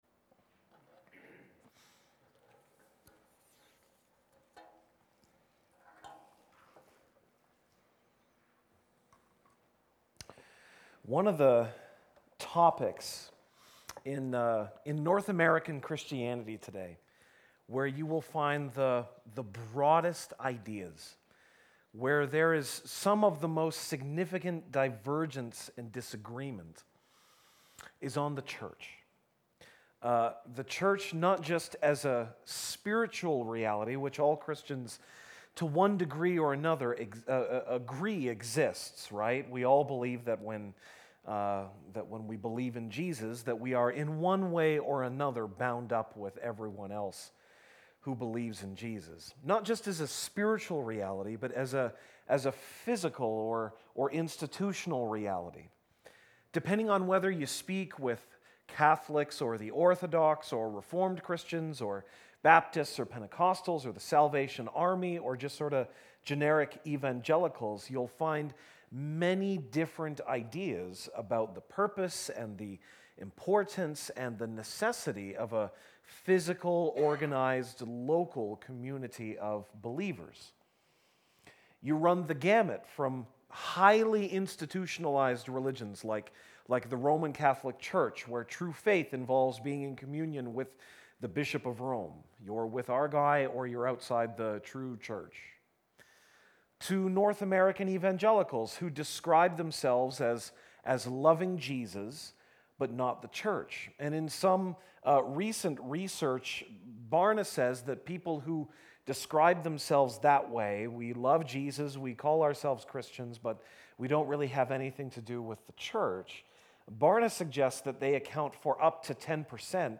September 23, 2018 (Sunday Evening)